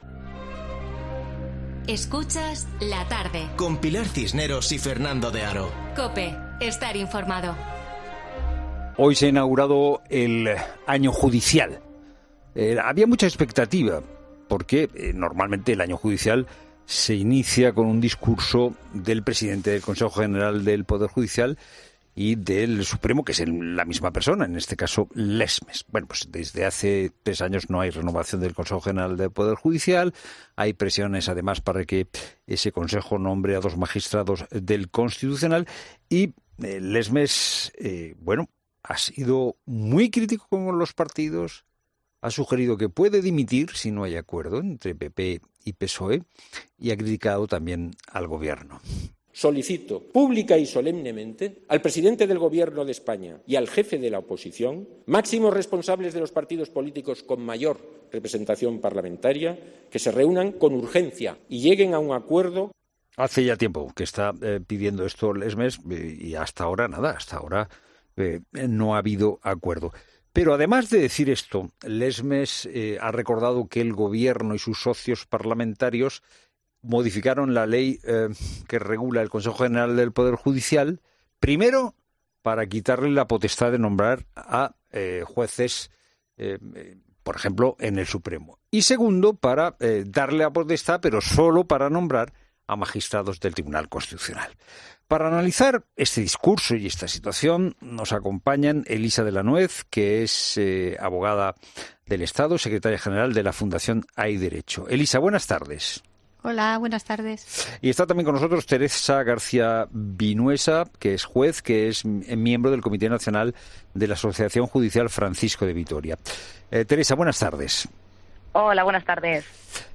entrevista a dos expertas en derecho después de las declaraciones del presidente del Consejo General del Poder Judicial